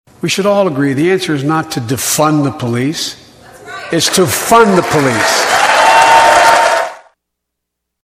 From the Russian invasion of Ukraine to a host of domestic issues, President Joe Biden spent over an hour giving his status report and vision for the future during his initial State of the Union address Tuesday.
0718-biden-fund-the-police.mp3